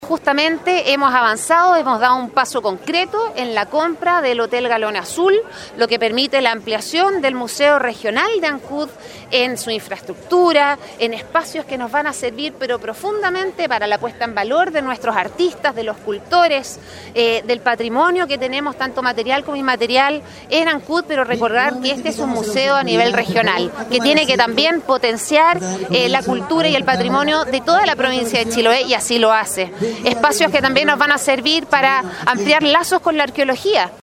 Amanda Milosevich, seremi de Cultura y las Artes, entregó detalles de la compra y cuáles serán los beneficios para la comunidad.
CUÑA-1-AMANDA-MILOSEVICH.mp3